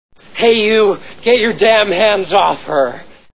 Back to the Future Movie Sound Bites
hey.wav